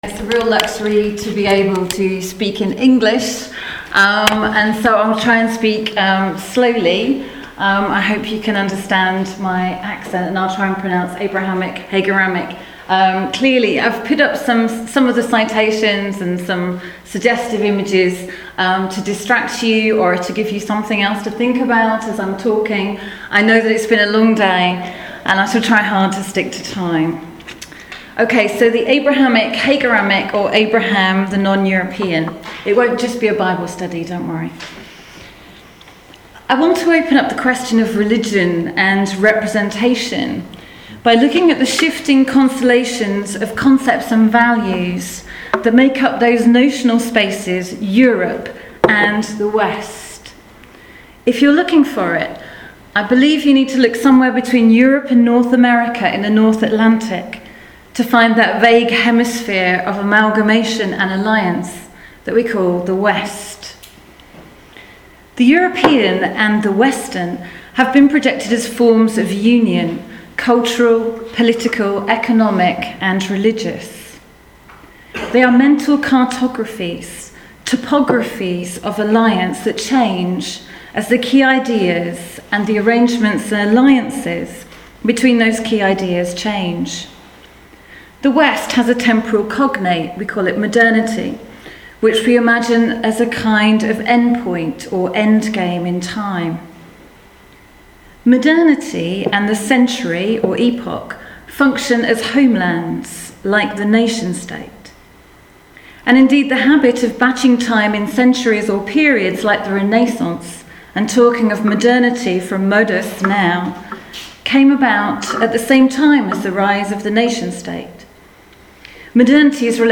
Öffentlicher Abendvortrag der 3. Internationalen Sommerakademie des ZfL 2013: Religion und Repräsentation. Zum Problem der Religionen in kulturwissenschaftlicher Forschung